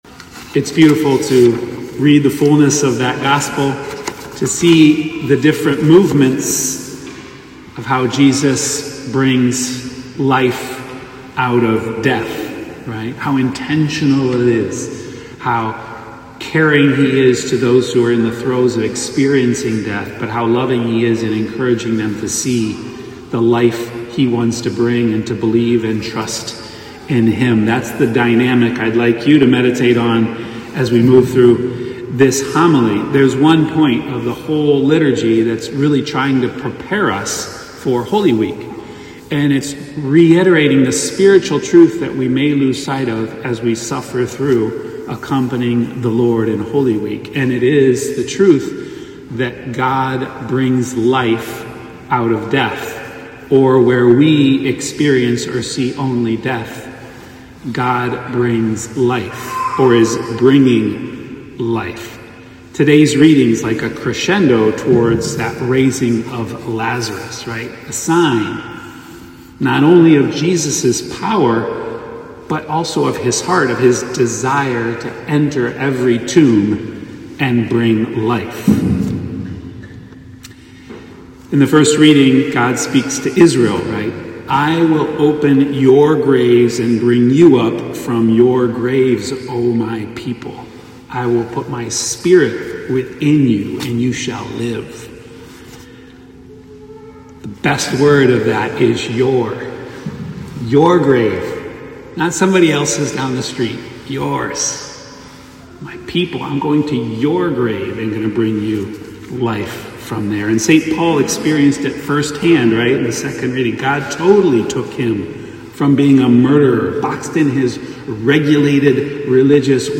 RCNY_homily.mp3